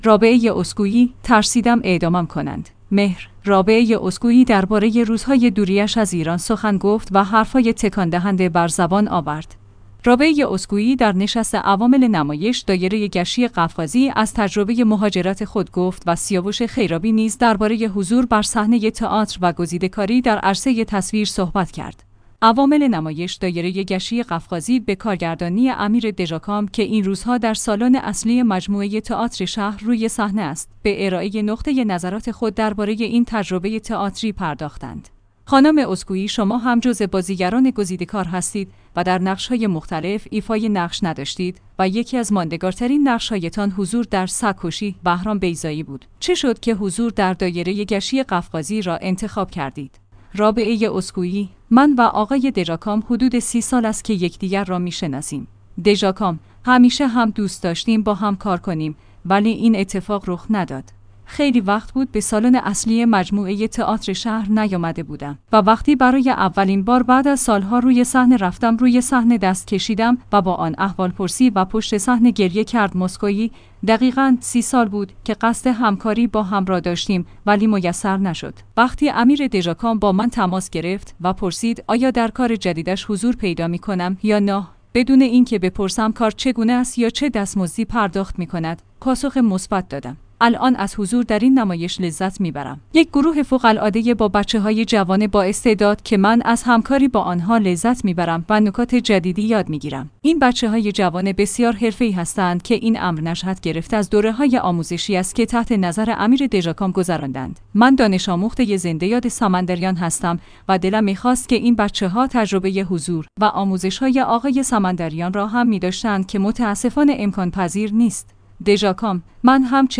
رابعه اسکویی در نشست عوامل نمایش «دایره گچی قفقازی» از تجربه مهاجرت خود گفت و سیاوش خیرابی نیز درباره حضور بر صحنه تئاتر و گزیده‌کاری در عرصه تصویر صحبت کرد.